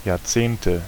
Ääntäminen
Ääntäminen Tuntematon aksentti: IPA: /jaːɐ̯ˈt͡seːntə/ Haettu sana löytyi näillä lähdekielillä: saksa Käännöksiä ei löytynyt valitulle kohdekielelle. Jahrzehnte on sanan Jahrzehnt monikko.